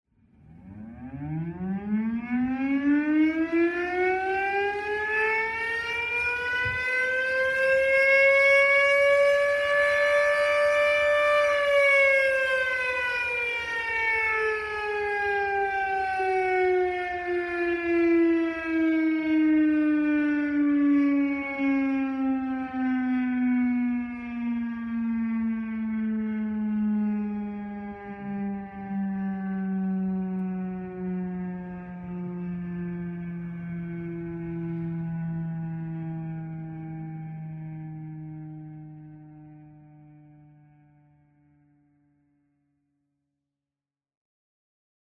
proctor-0945-curfew-siren.mp3